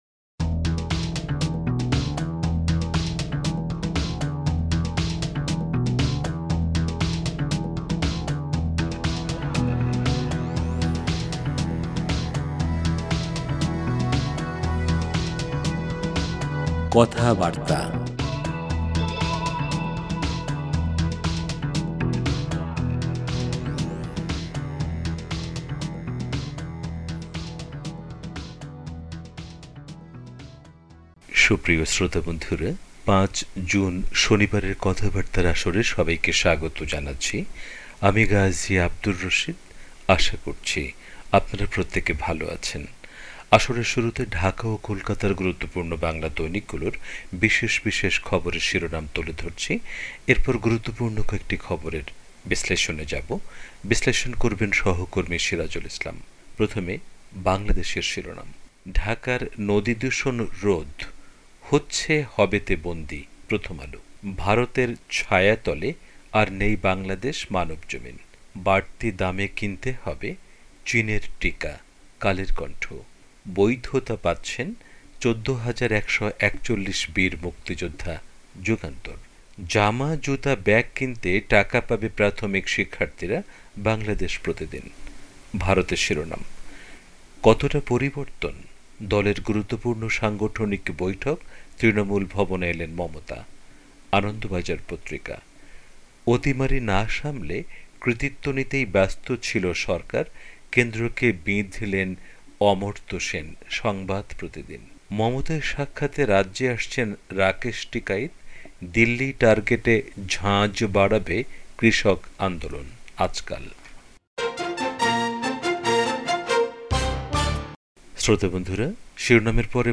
আসরের শুরুতে ঢাকা ও কোলকাতার গুরুত্বপূর্ণ বাংলা দৈনিকগুলোর বিশেষ বিশেষ খবরের শিরোনাম তুলে ধরছি। এরপর গুরুত্বপূর্ণ কয়েকটি খবরের বিশ্লেষণে যাবো।